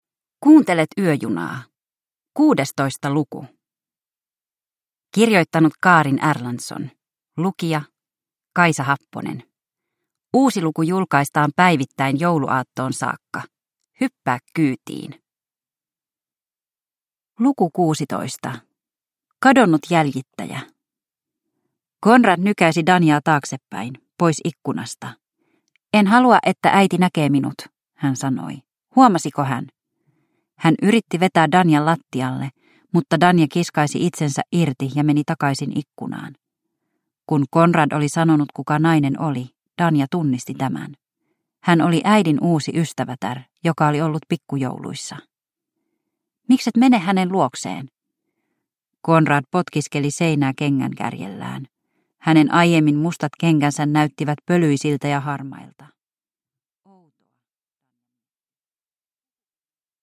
Yöjuna luku 16 – Ljudbok